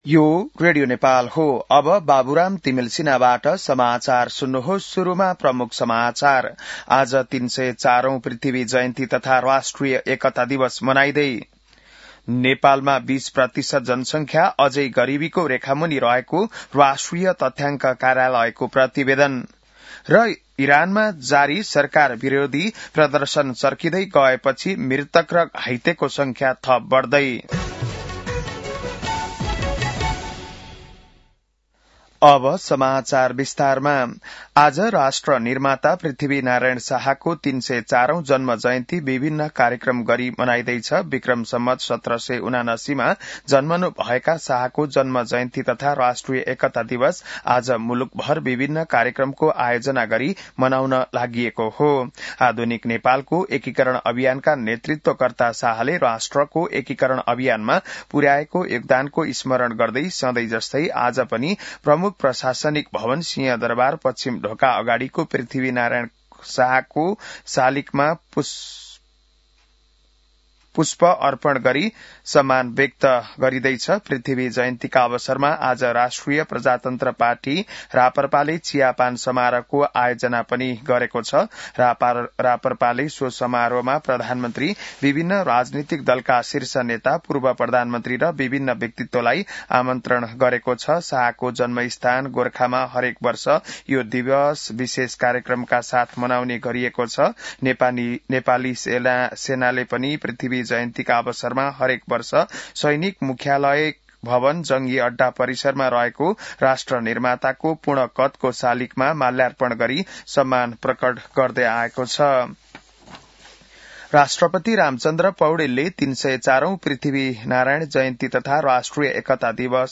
बिहान ९ बजेको नेपाली समाचार : २७ पुष , २०८२